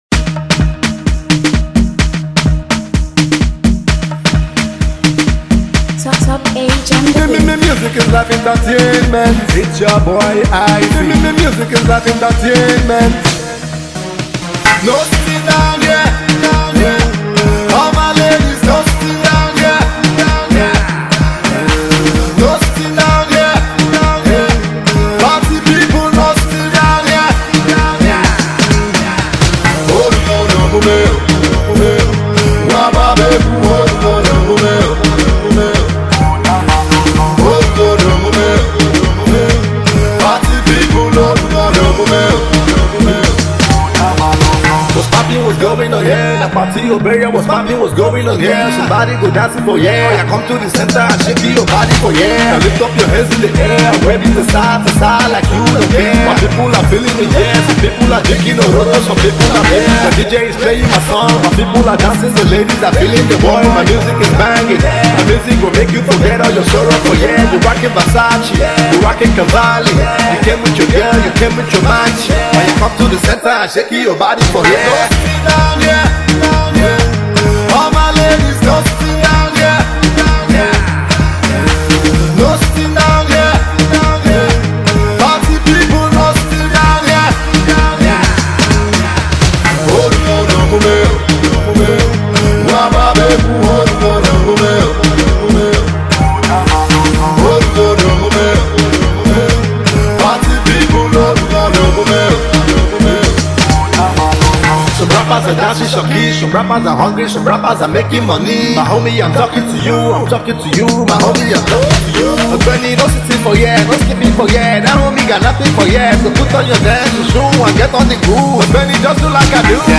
AudioDancePop